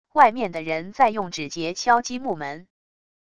外面的人在用指节敲击木门wav音频